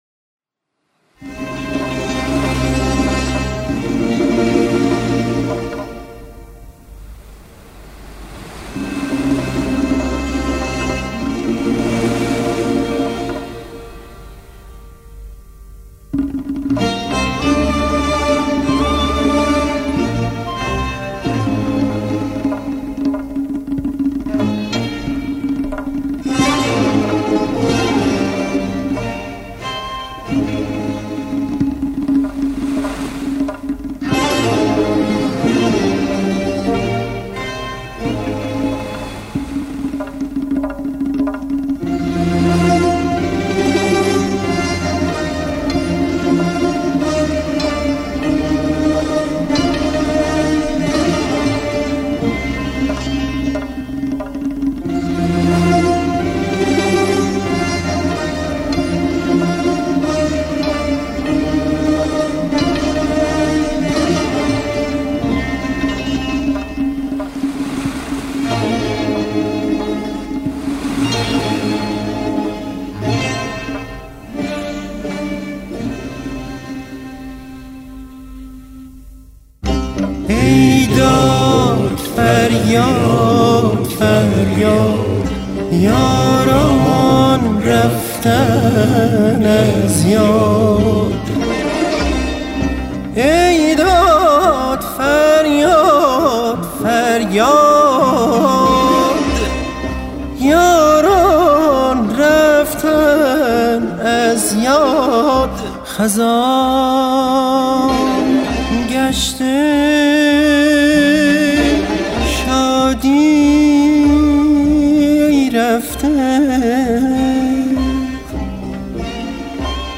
در دستگاه‌ : نوا